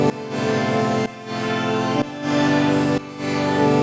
GnS_Pad-MiscB1:2_125-A.wav